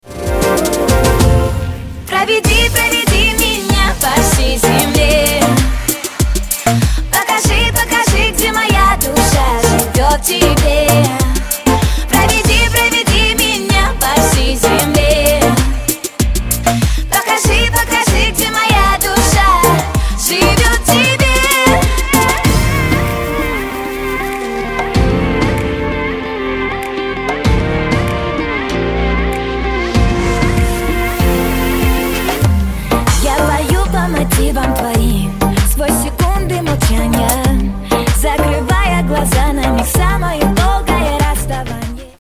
• Качество: 320, Stereo
поп
громкие
женский вокал
dance
звонкие